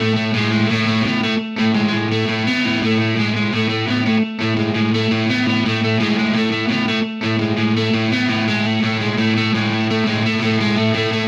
Indie Pop Guitar 03.wav